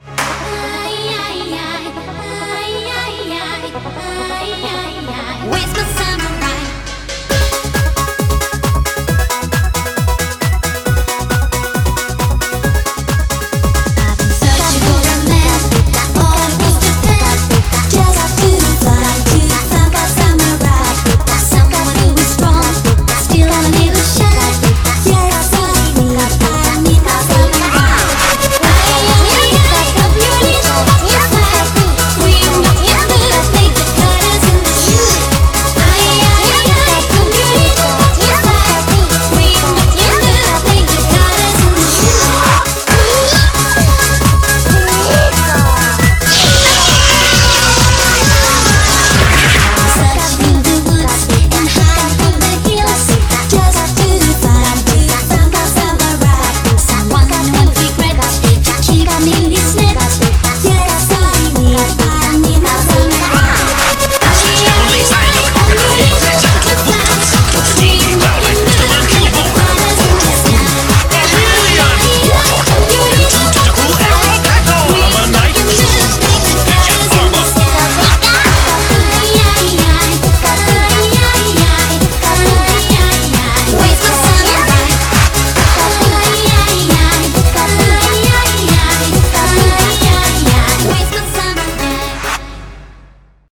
BPM135
Audio QualityMusic Cut